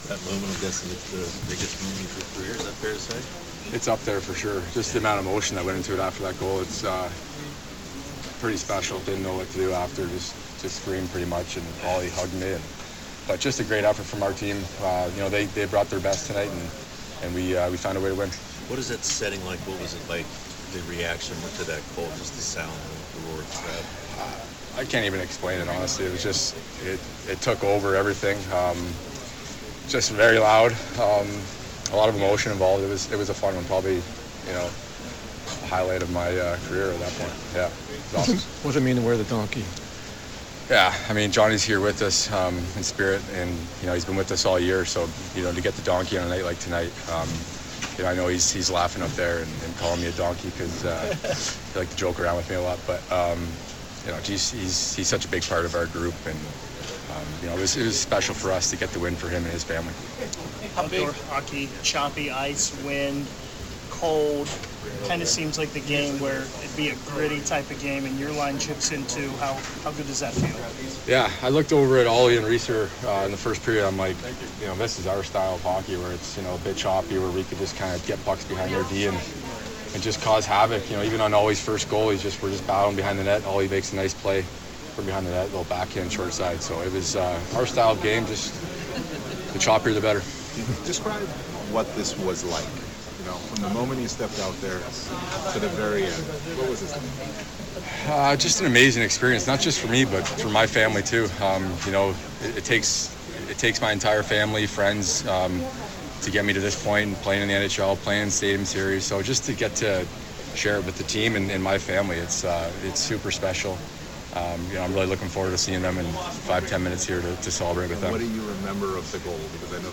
Justin Danforth CBJ center On CBJ win & his GW goal in Stadium Series win over Detroit, 5-3 March 1, 2025